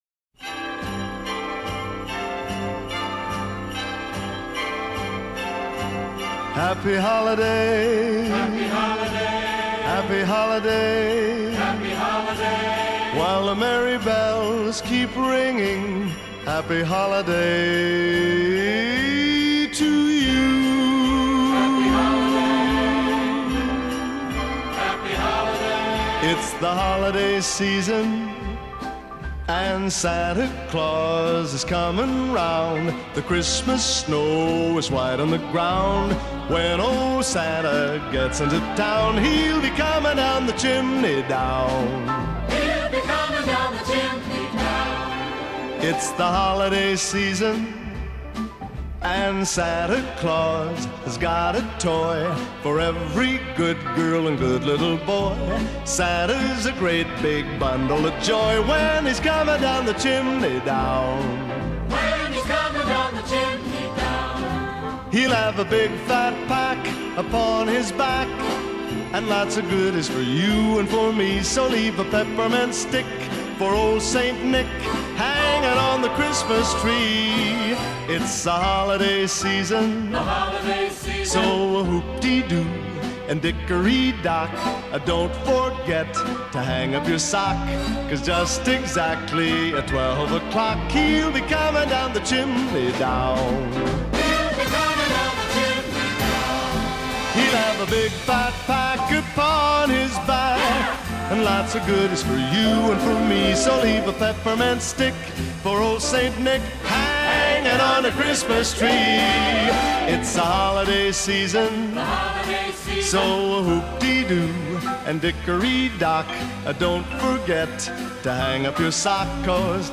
a medley
It’s pure Christmas joy.